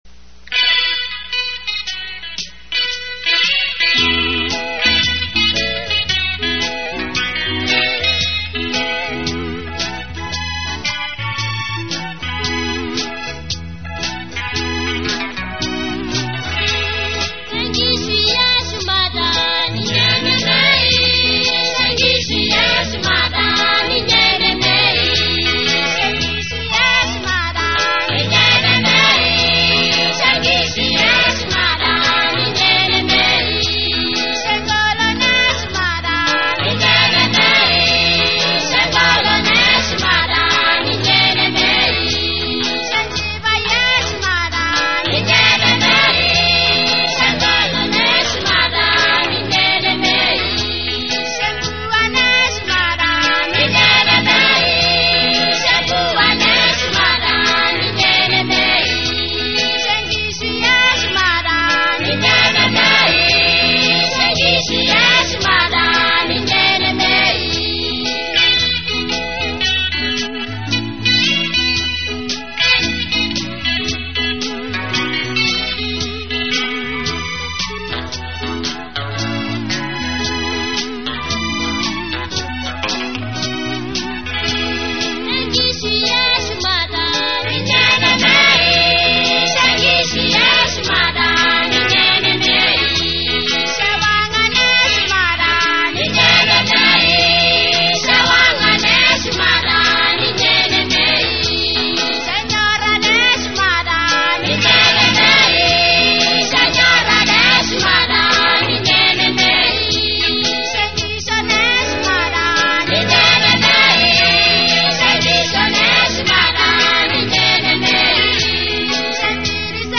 And some Christian songs in Maasai/Samburu.